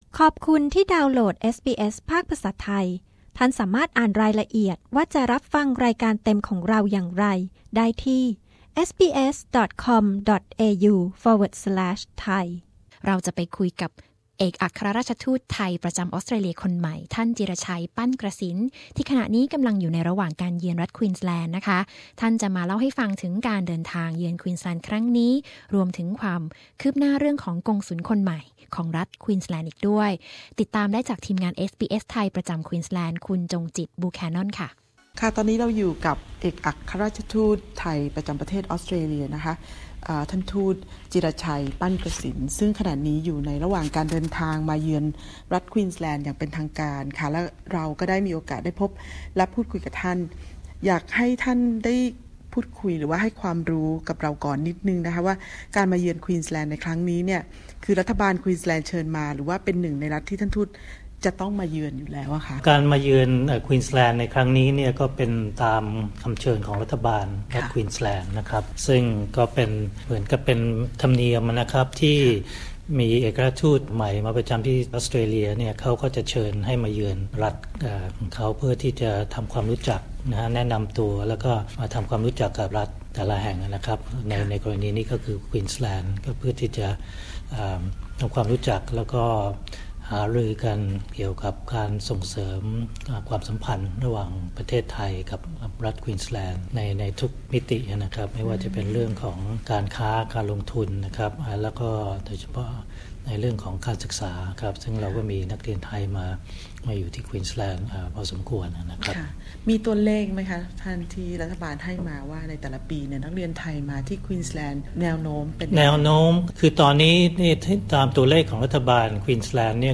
ท่านจิระชัย ปั้นกระษิณ เอกอัครราชทูตไทยประจำออสเตรเลียคนใหม่ พูดคุยกับเอสบีเอส ไทย เรื่องการเยือนรัฐควีนส์แลนด์เมื่อเร็วๆ นี้ พร้อม กล่าวถึงเรื่องกงสุลคนใหม่ของรัฐควีนสแลนด์